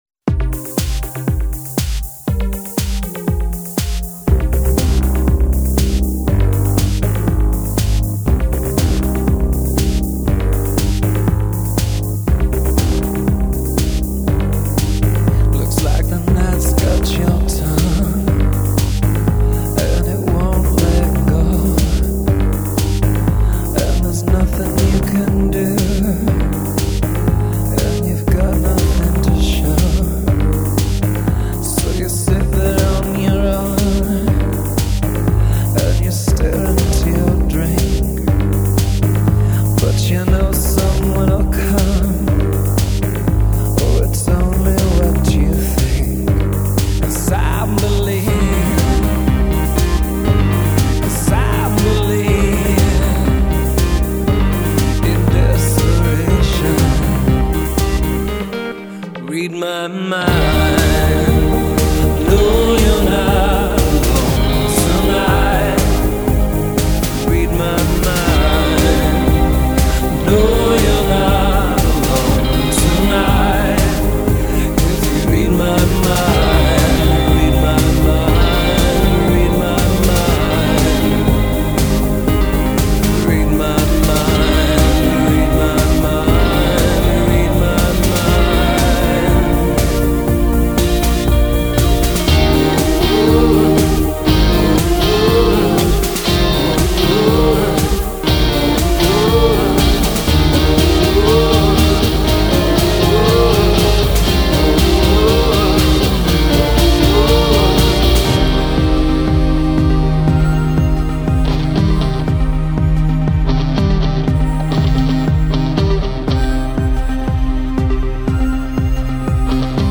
sinister electronic rock
producer/singer combination
80's influenced digitally constructed beats